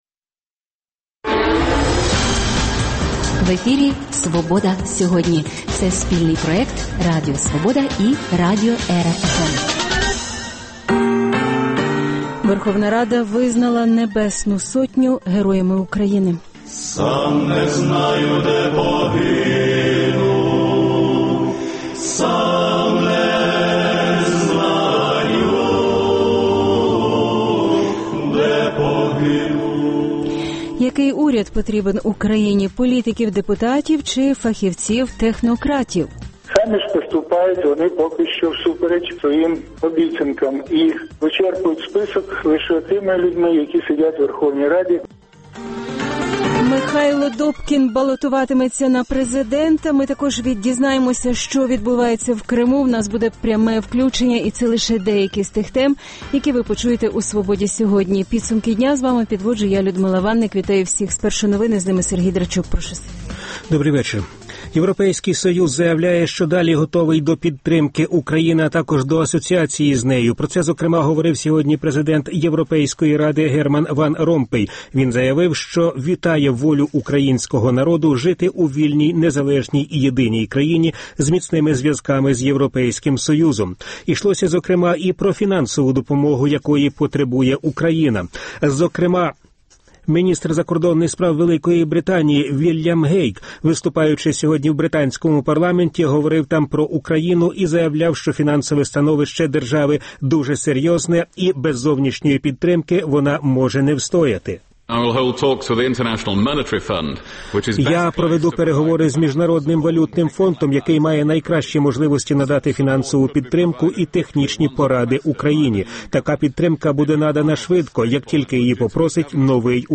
Верховна Рада визнала Небесну Сотню Героями України Який уряд потрібен Україні – політиків-депутатів чи фахівців-технократів? Михайло Добкін балотуватиметься на президента Що відбувається в Криму – у нас буде пряме включення